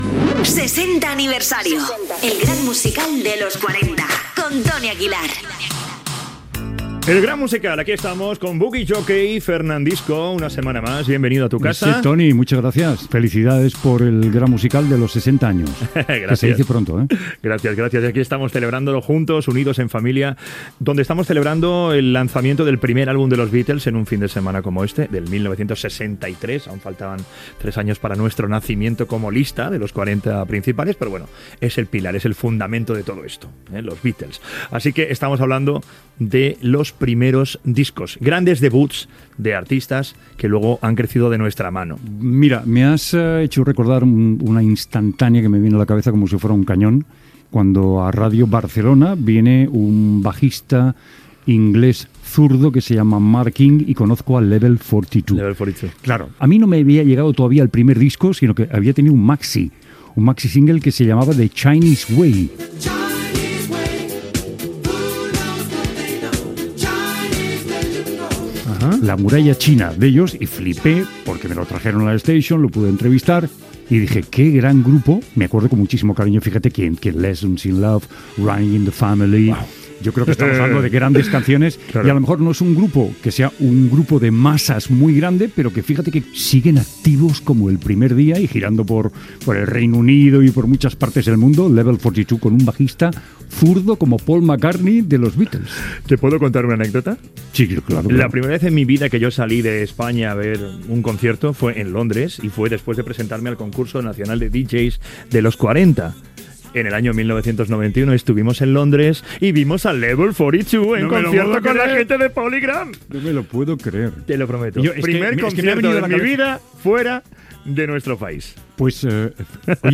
Fragment del programa fet amb Fernando Martínez "Fernandisco".
Musical